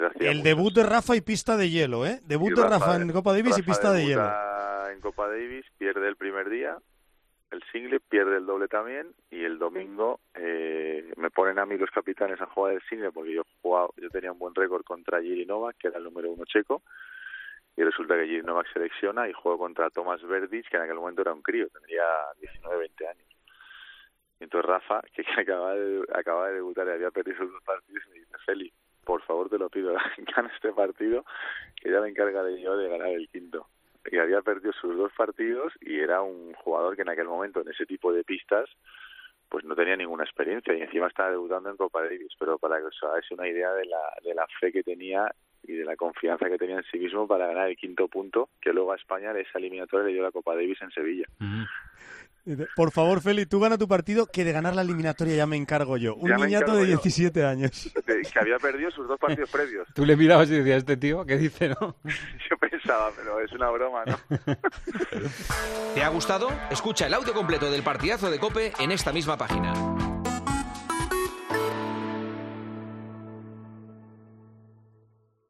AUDIO: El jugador que este jueves ha disputado su último partido de tenis como profesional pasó por 'El Partidazo de COPE' para hablar sobre algunas...